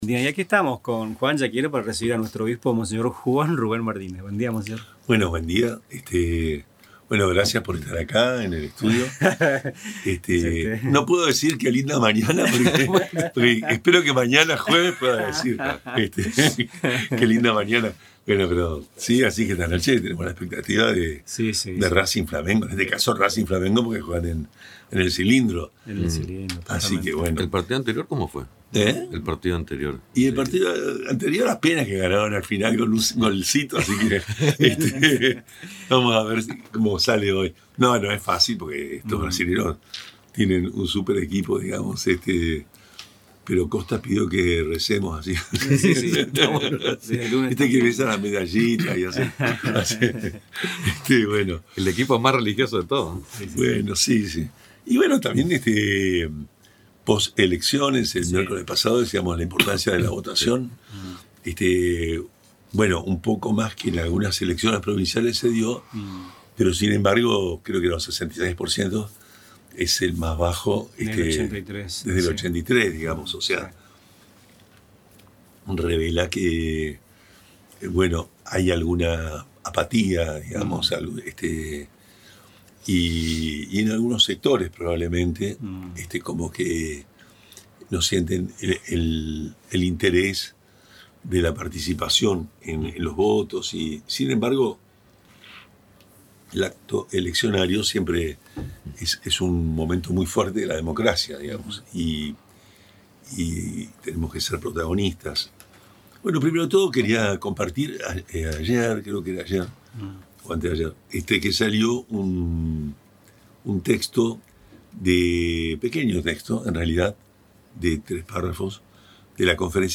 En su tradicional visita a los estudios de Radio Tupambaé, el obispo de la diócesis de Posadas, monseñor Juan Rubén Martínez, reflexionó sobre el reciente proceso electoral del domingo 26 de octubre y llamó a la sociedad argentina a cultivar la humildad, el diálogo y el compromiso ciudadano.